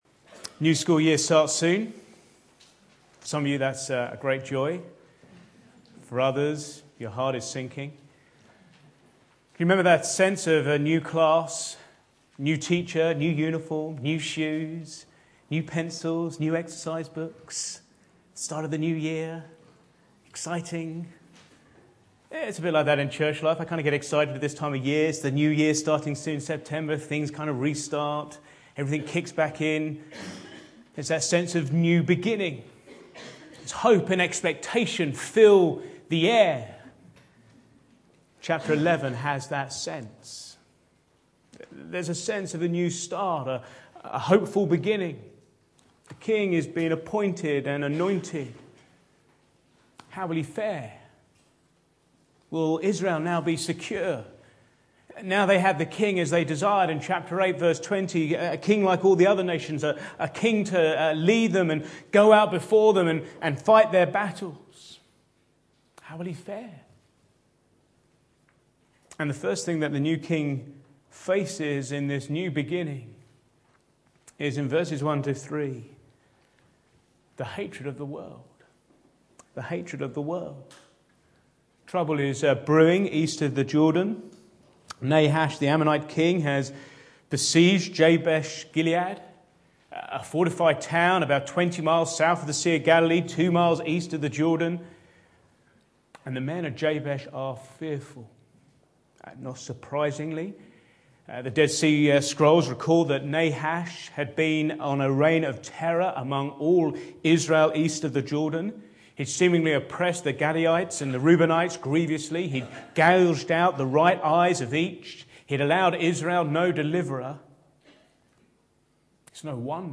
Back to Sermons A Hopeful Beginning